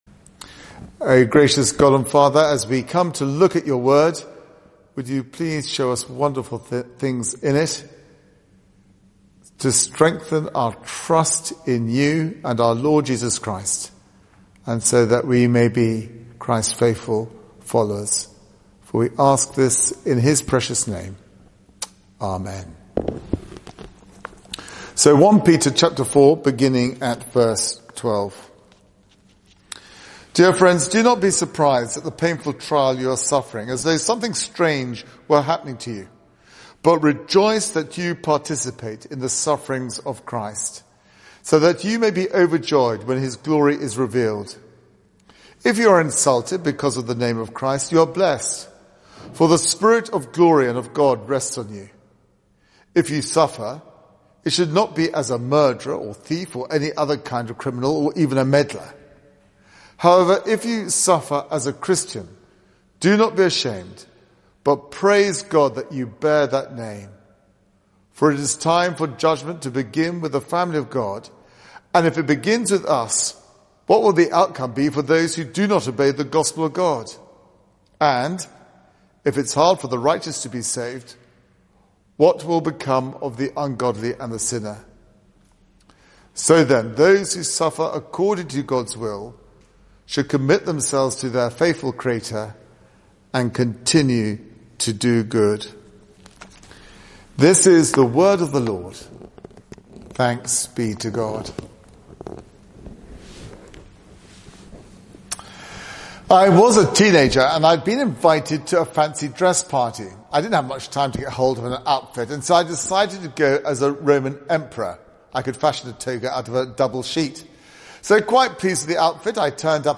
Media for 6:30pm Service on Sun 07th Mar 2021 18:30 Speaker
Sermon (Audio)